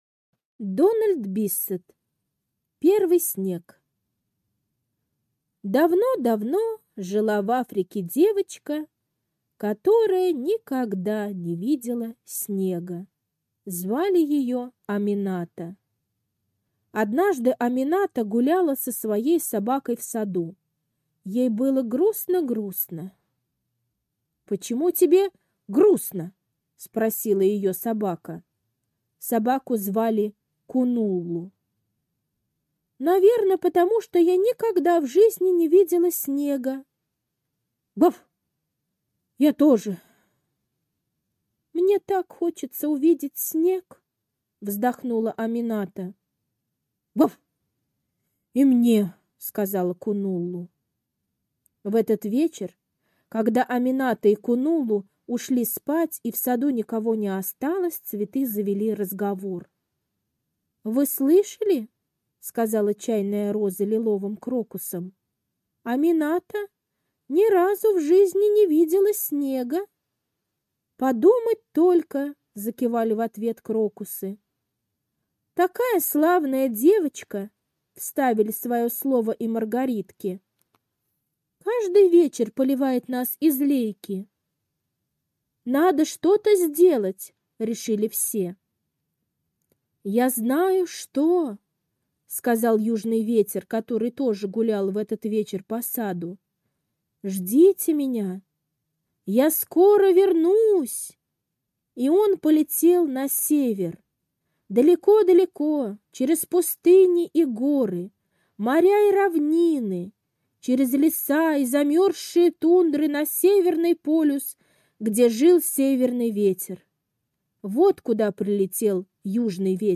Первый снег - аудиосказка Биссета Д. Сказка про то, как южный ветер помог девочке из Африки увидеть первый раз в жизни снег.